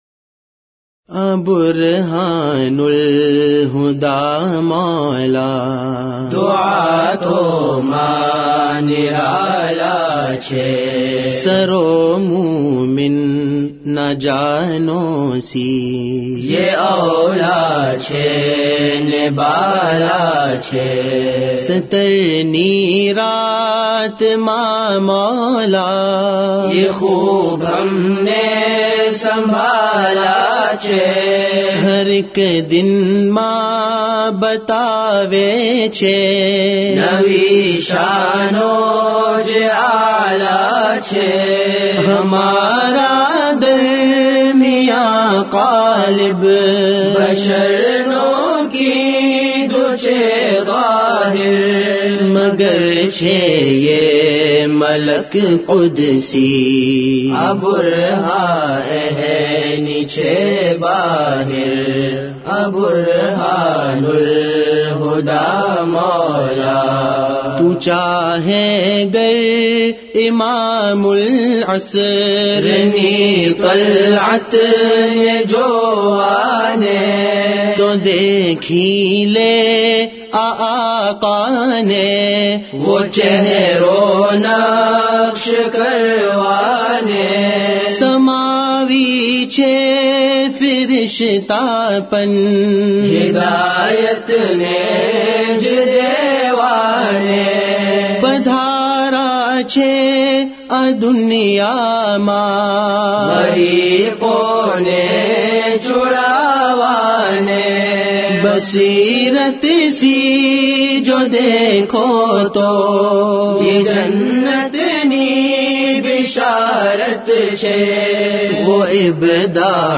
Madeh